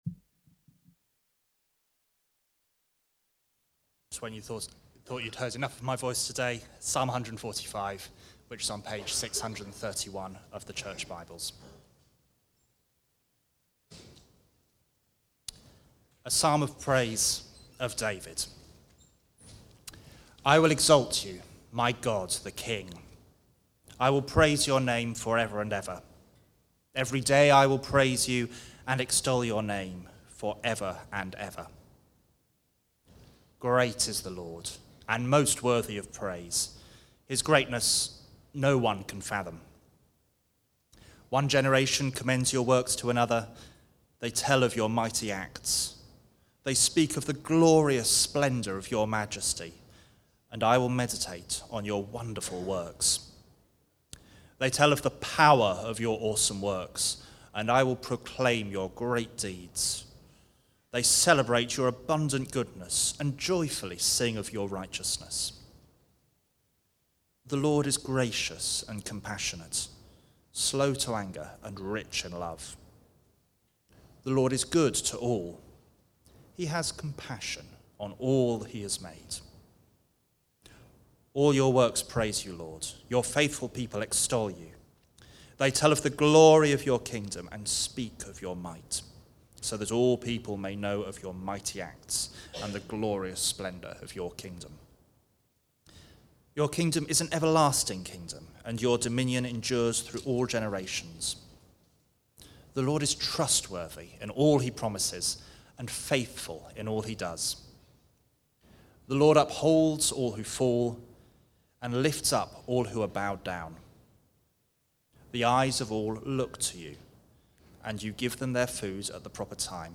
Preaching
Recorded at Woodstock Road Baptist Church on 08 February 2026.